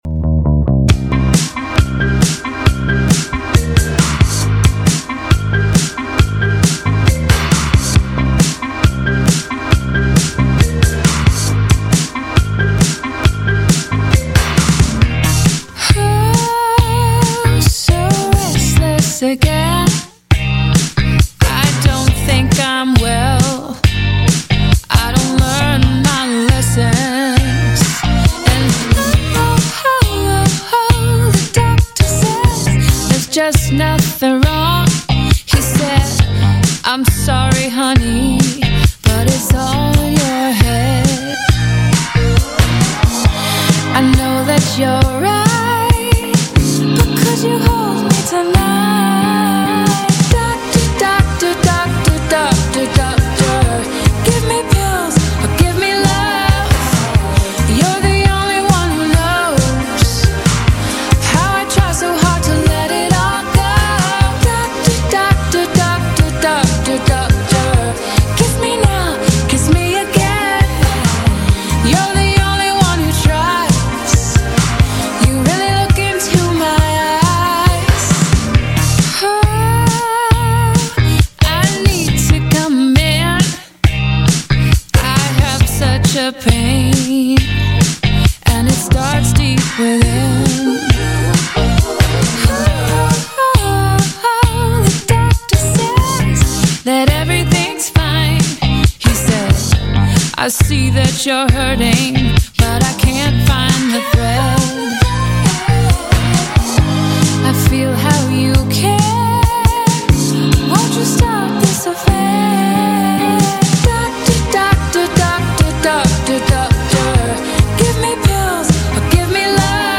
Interview on Party 934